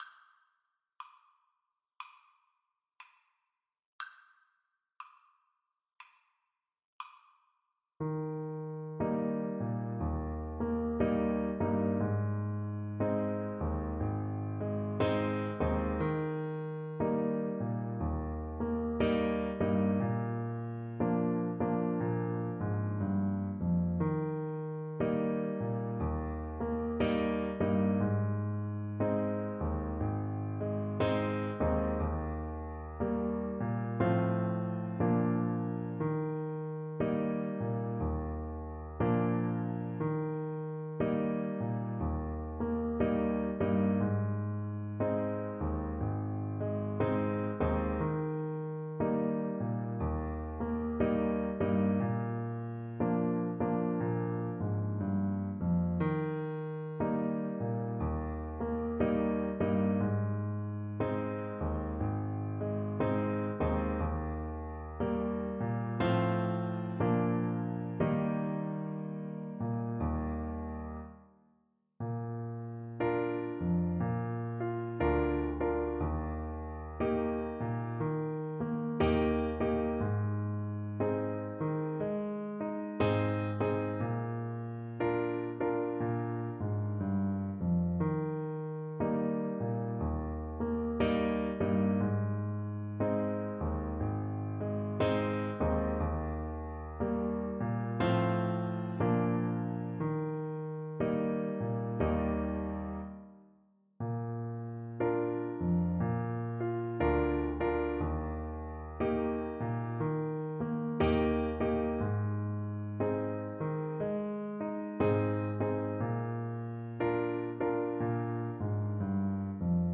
Play (or use space bar on your keyboard) Pause Music Playalong - Piano Accompaniment transpose reset tempo print settings full screen
Tuba
"Old Folks at Home" (also known as "Swanee River", "Swanee Ribber" [from the original lyrics] or "Suwannee River") is a minstrel song written by Stephen Foster in 1851.
Eb major (Sounding Pitch) (View more Eb major Music for Tuba )
4/4 (View more 4/4 Music)
Swing Eighths